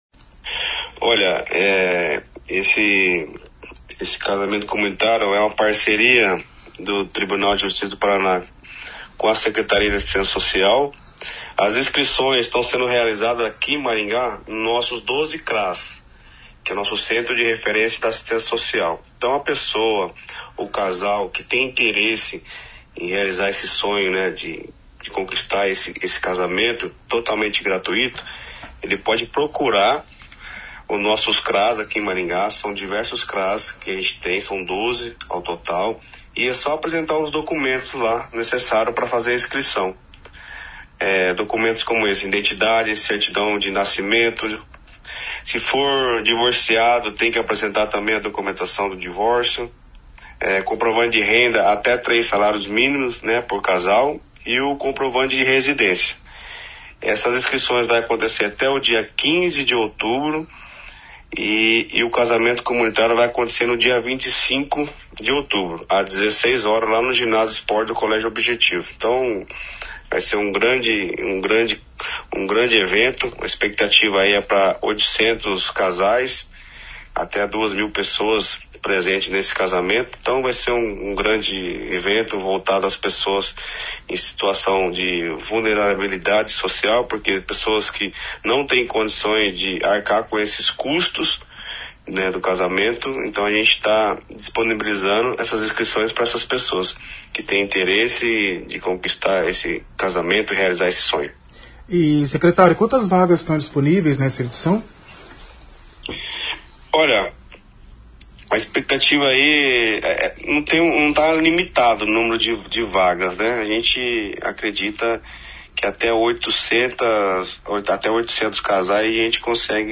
A ação, voltada a casais em situação de vulnerabilidade social, é uma oportunidade de oficialização da união de forma totalmente gratuita. O secretário de Assistência Social, Políticas sobre Drogas e Pessoa Idosa (SAS), Leandro Bravin, explica como funciona o processo de inscrição e quem pode participar: